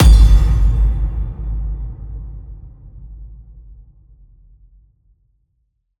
Impact,Sound Design,Hit,Chime,Resonant Hit,Chime Accent,Tinkle,Fast.ogg